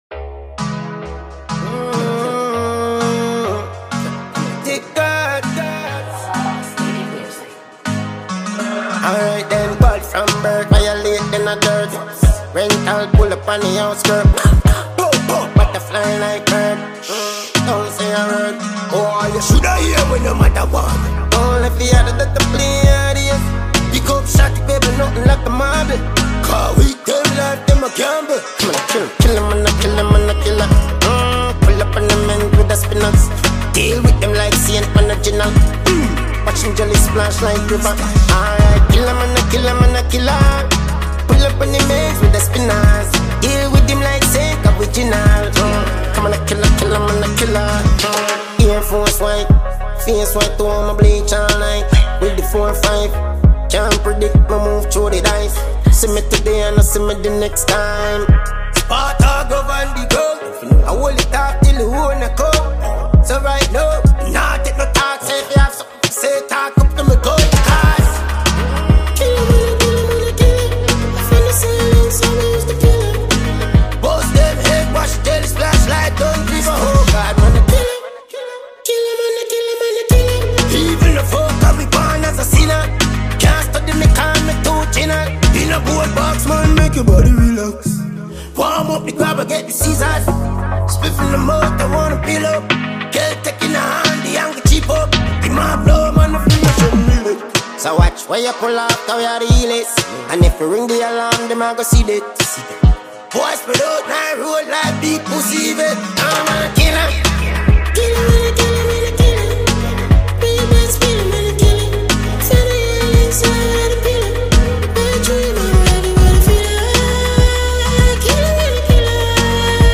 Dancehall/HiphopMusic
Talented Jamaican dancehall recording artist